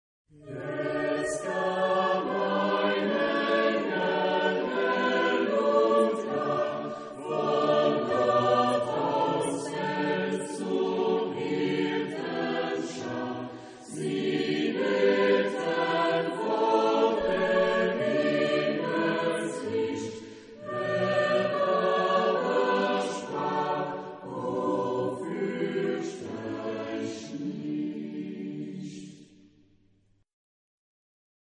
Zeitepoche: 17. Jh.
Genre-Stil-Form: geistlich ; Choral
Chorgattung: SATB  (4 gemischter Chor Stimmen )
Tonart(en): D-Dur